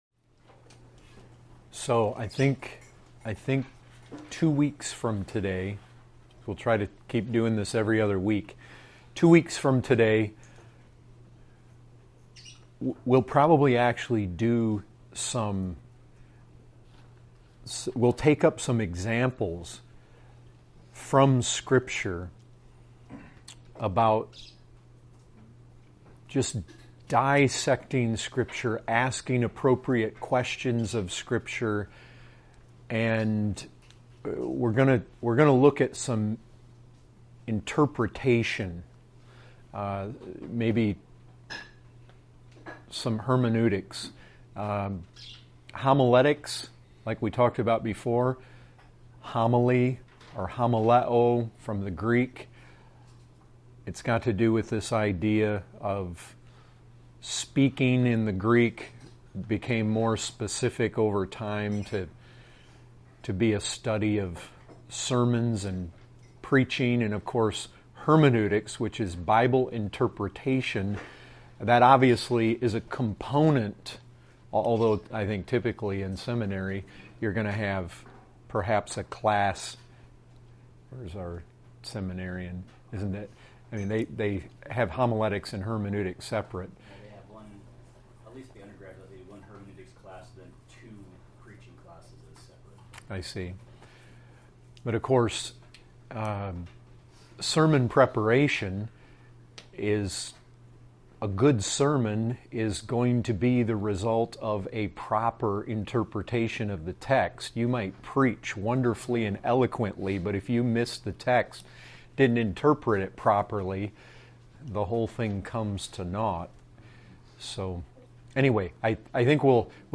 Category: Bible Studies